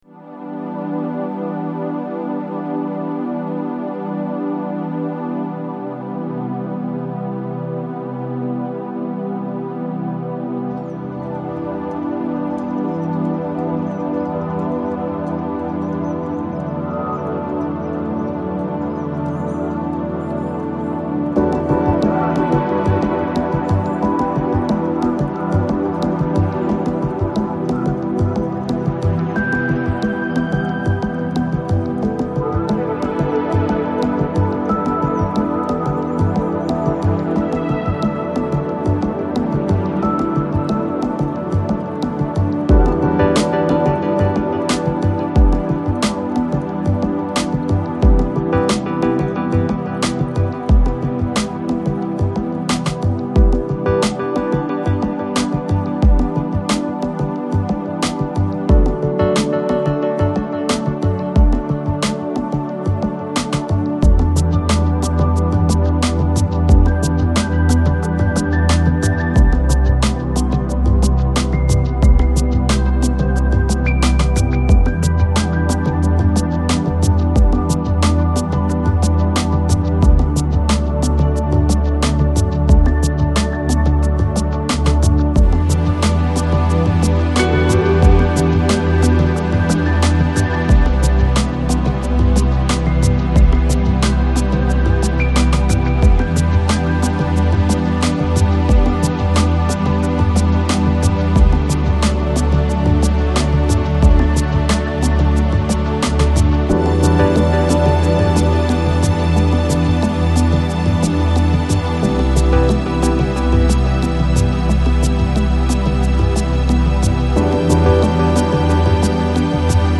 Lounge, Chill Out, Chill House, Downbeat Год издания